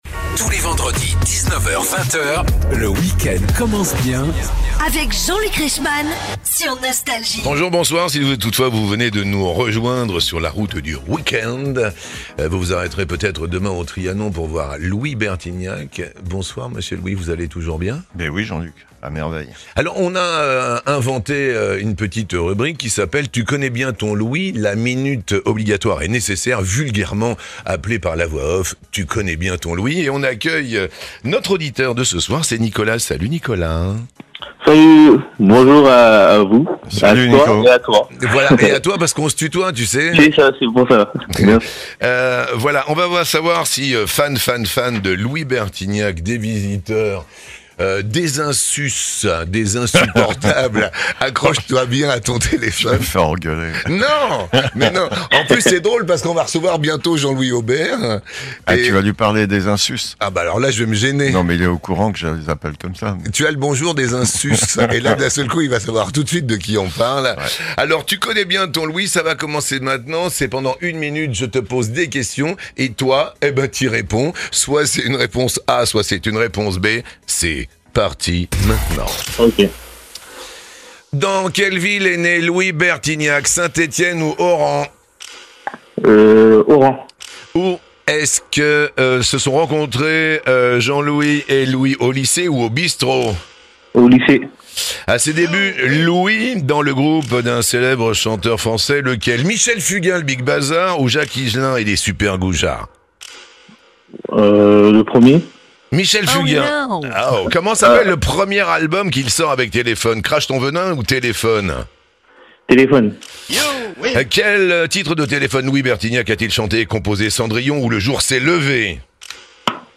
Invité de Jean-Luc Reichmann dans "On se tutoie ?...", Louis Bertignac dévoile les belles rencontres de sa carrière !